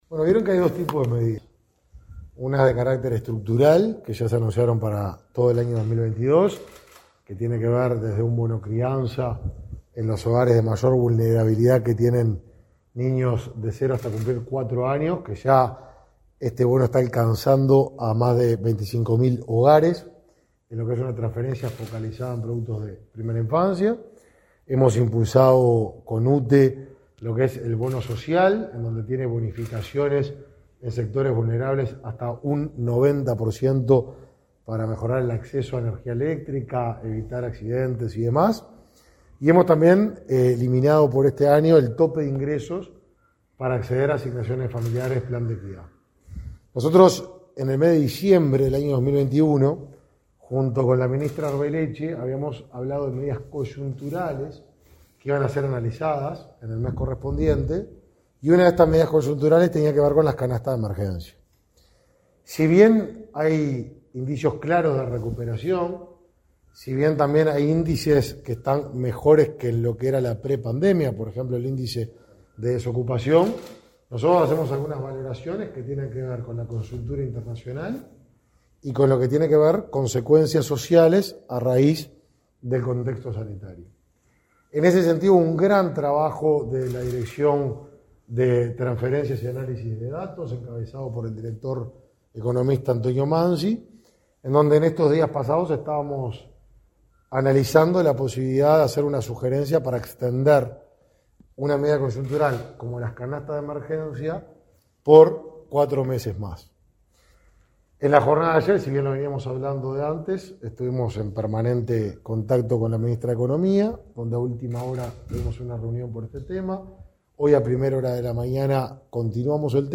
Declaraciones a la prensa del ministro de Desarrollo Social, Martín Lema